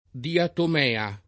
[ diatom $ a ]